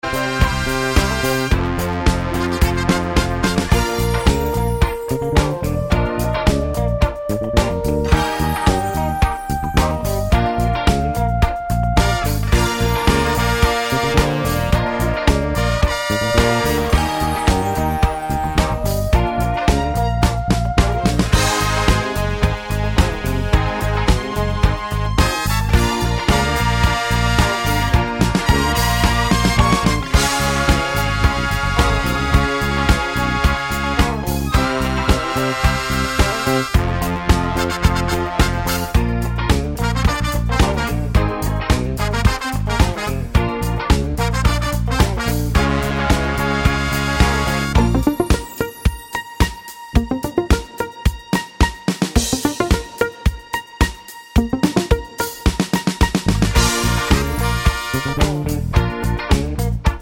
no Backing Vocals Disco 4:24 Buy £1.50